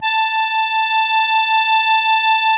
MUSETTE 1.15.wav